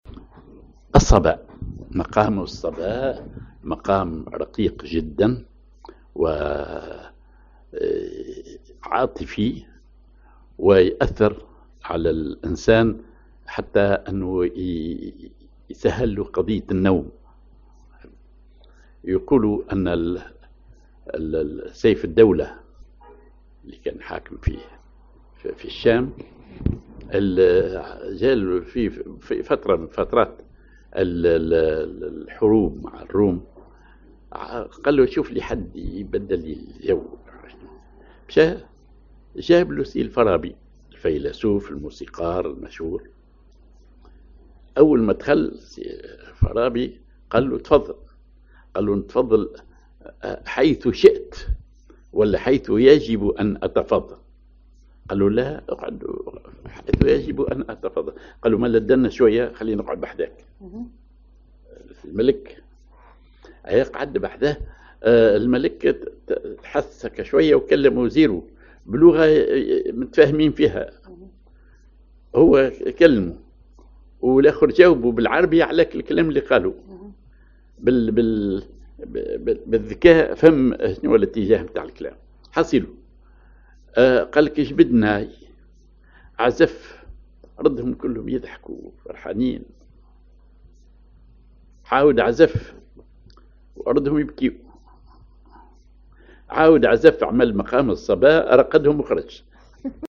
Maqam ar صبا
Rhythm ID سماعي ثقيل
genre سماعي